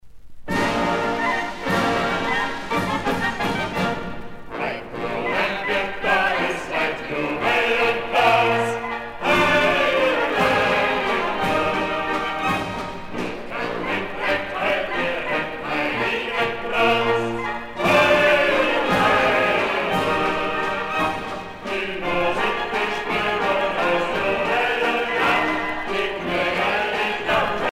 danse : ländler
Pièce musicale éditée